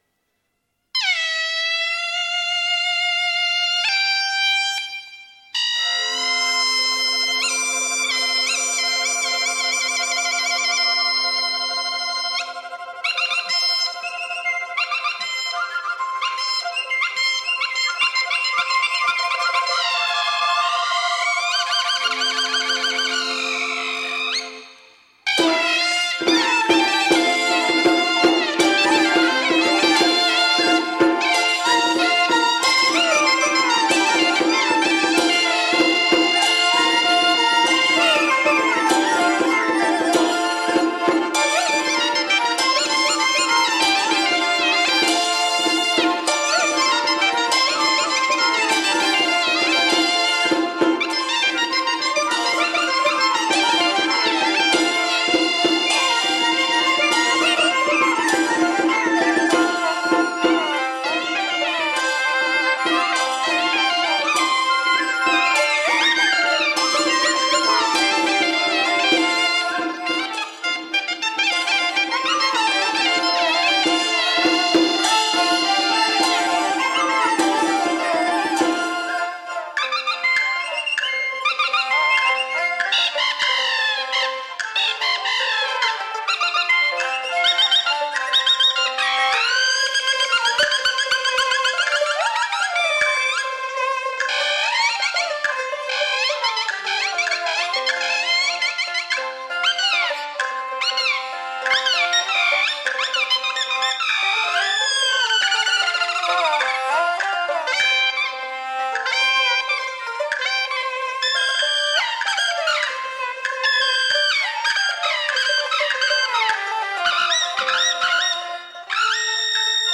吹打乐专辑